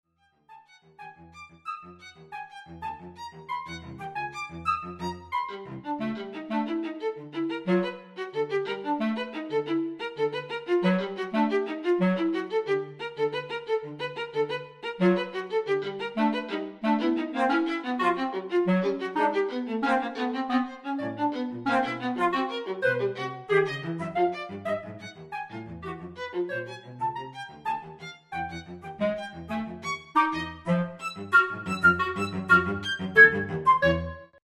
for flute, clarinet, violin, viola, cello (1995)